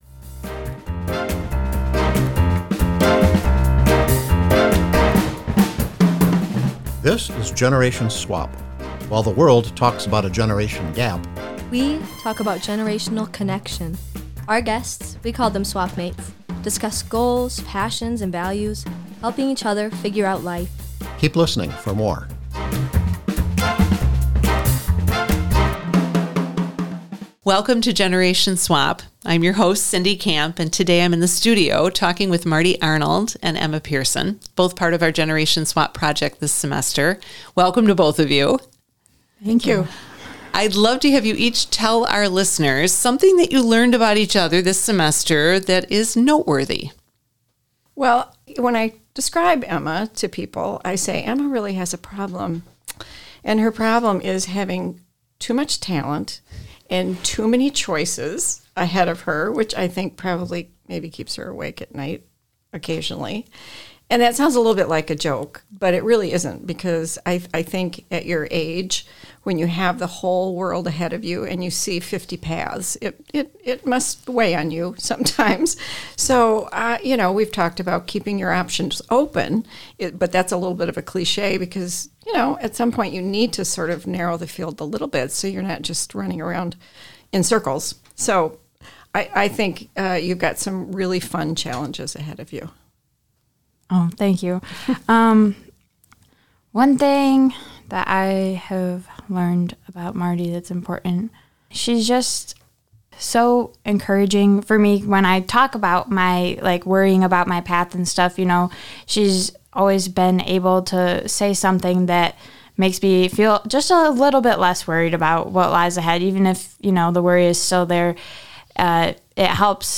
The course capstone project was a Generation Swap podcast interview exploring how generational differences can be a source of strength and growth.